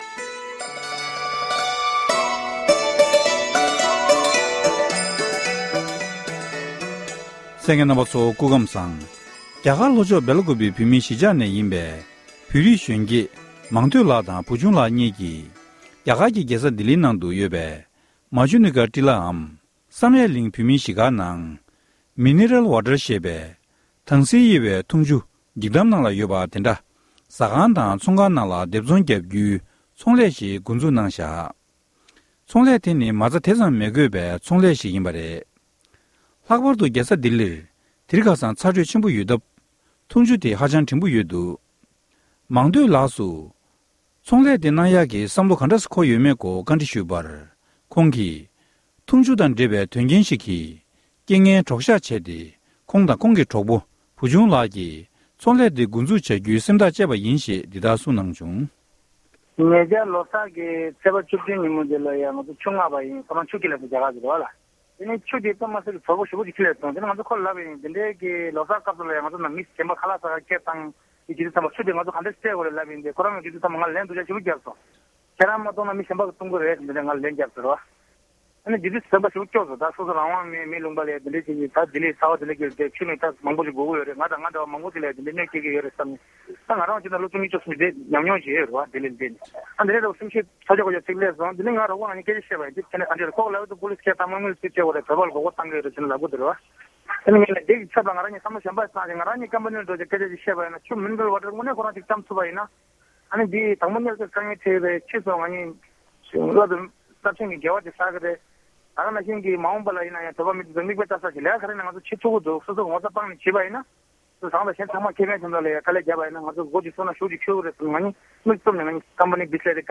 གནས་འདྲི